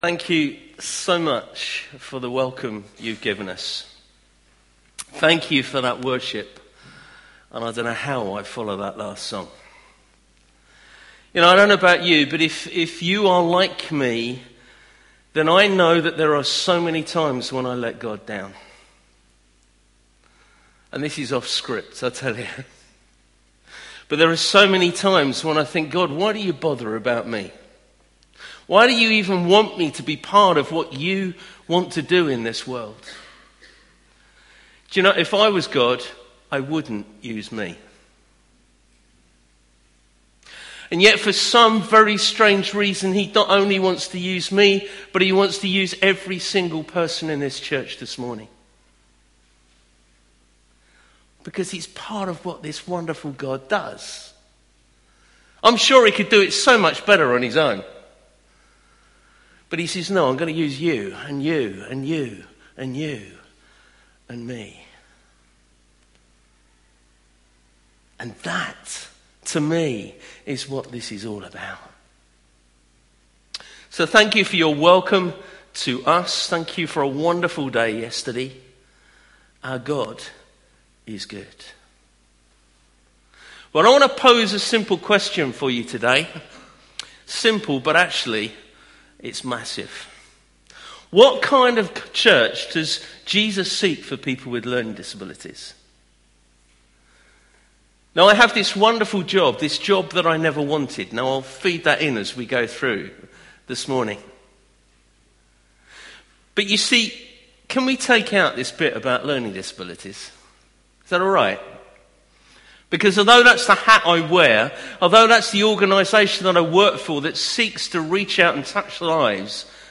Morning Service , Guest Speaker